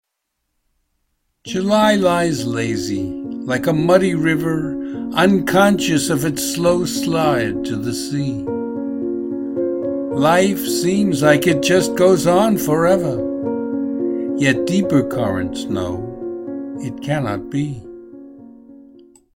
Hear me read the poem as an MP3 file.